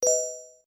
GemCollected.wav